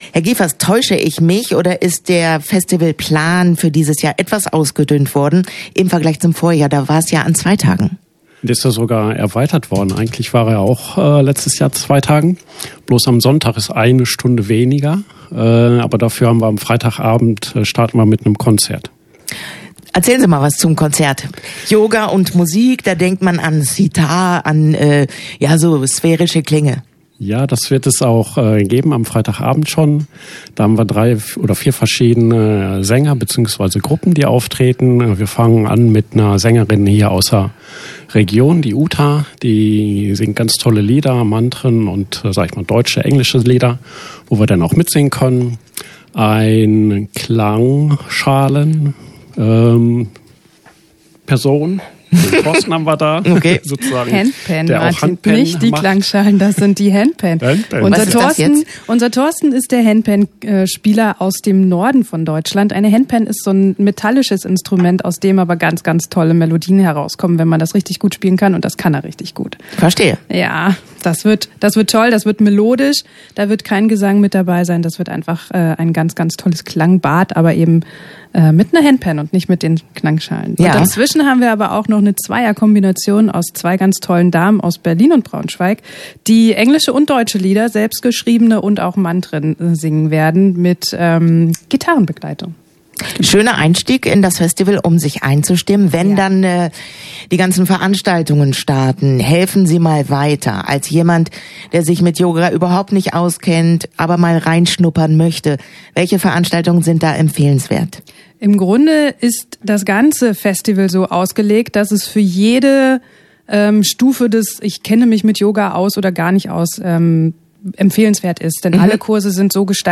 Interview-Yoga-Festival-6_nb.mp3